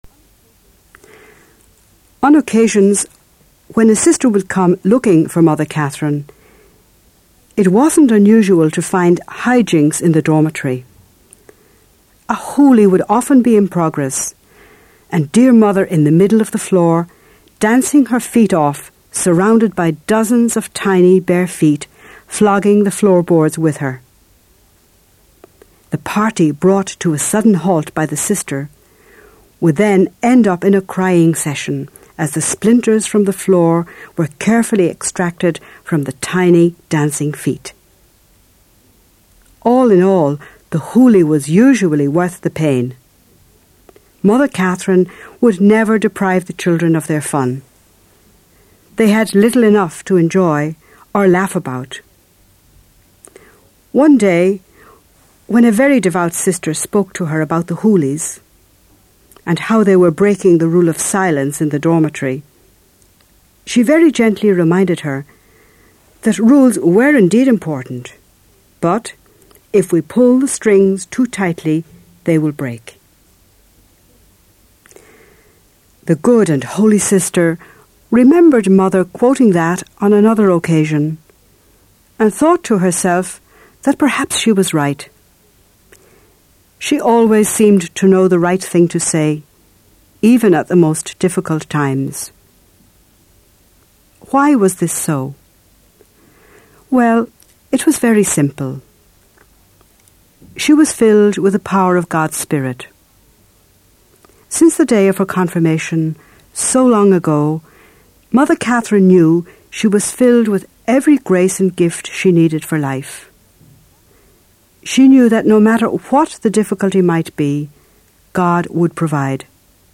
The story of Catherine McAuley for younger listeners